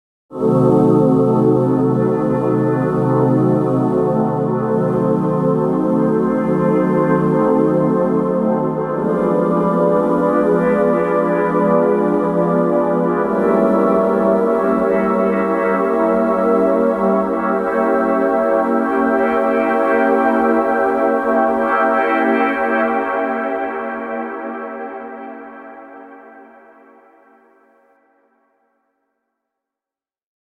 Dark Magical Choir Sound Effect
Description: Dark magical choir sound effect. This dark magical choir sound effect delivers haunting, mystical, ethereal, and powerful choral tones with an eerie, cinematic, suspenseful, and atmospheric vibe.
Dark-magical-choir-sound-effect.mp3